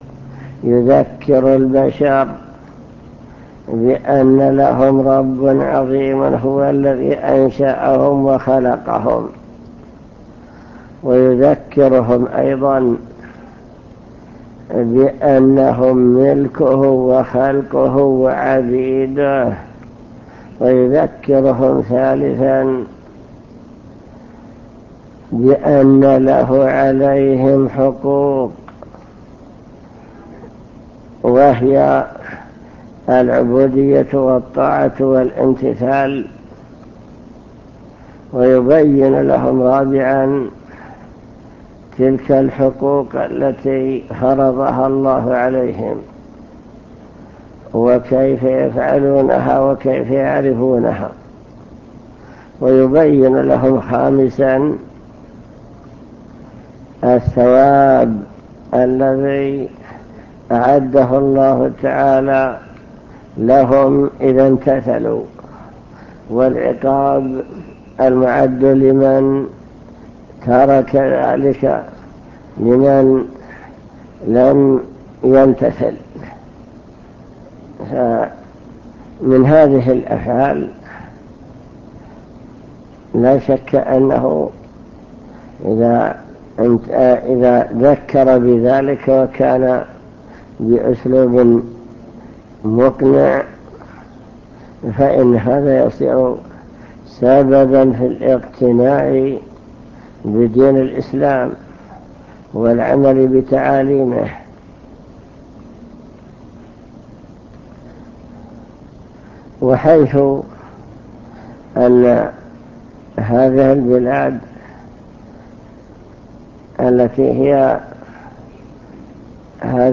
المكتبة الصوتية  تسجيلات - لقاءات  لقاء مع الشيخ بمكتب الجاليات